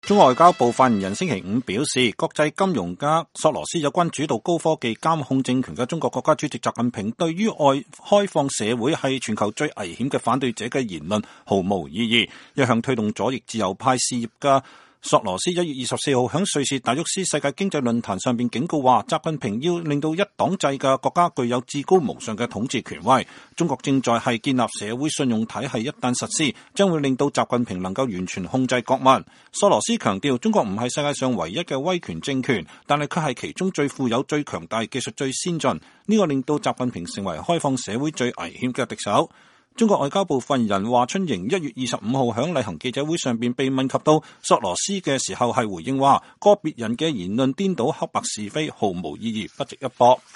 中國外交部發言人華春瑩
中國外交部發言人華春瑩1月25日在例行記者會上被問及索羅斯時回應稱，個別人的言論顛倒黑白和是非，毫無意義，不值得一駁。